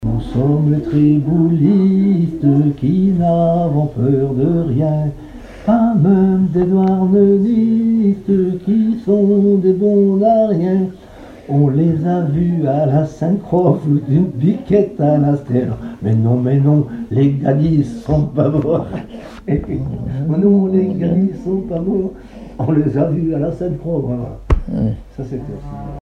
Base d'archives ethnographiques
Genre brève
Catégorie Pièce musicale inédite